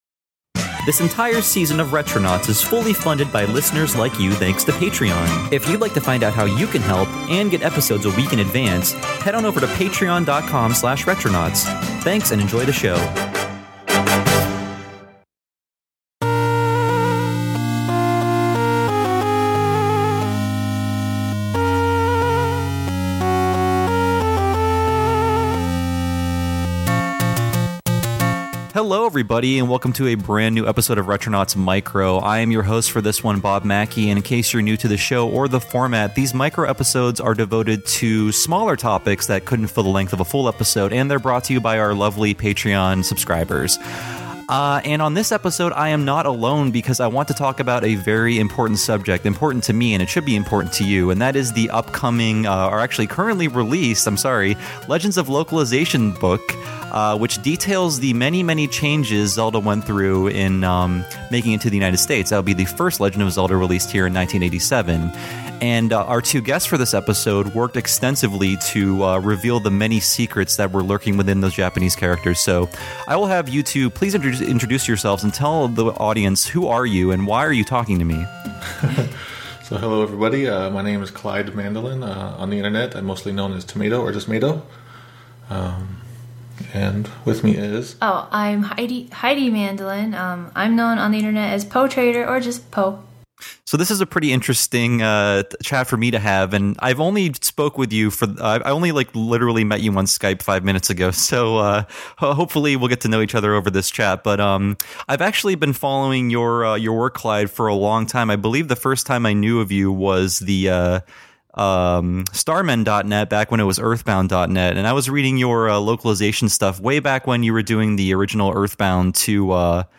Retronauts Micro 028: Legends of Localization Interview